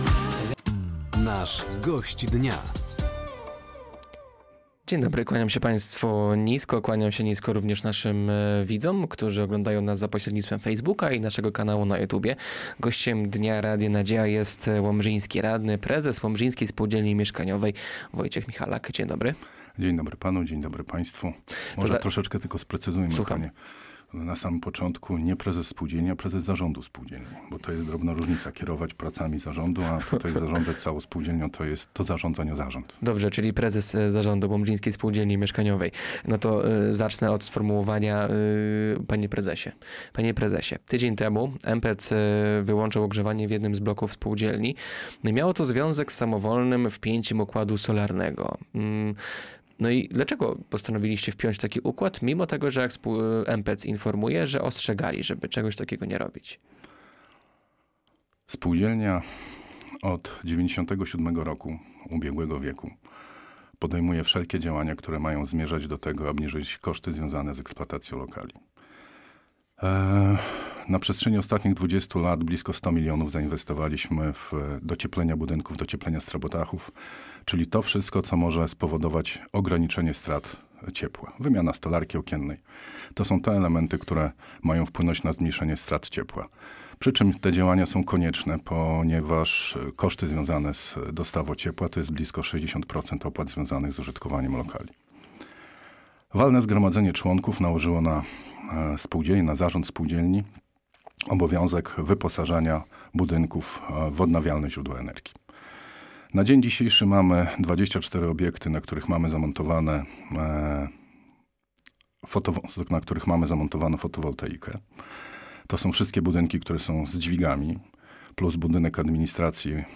O cenach za ciepło, gospodarce śmieciowej czy punktach sprzedaży alkoholu w Łomży – mówił na antenie Radia Nadzieja dzisiejszy Gość Dnia.